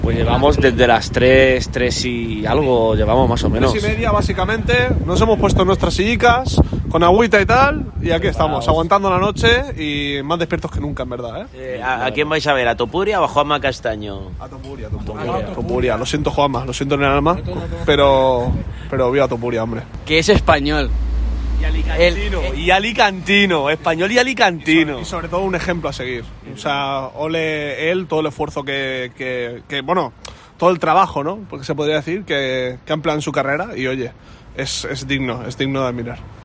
Oyentes de COPE esperan desde las 3 horas para conseguir una invitación para ver a Topuria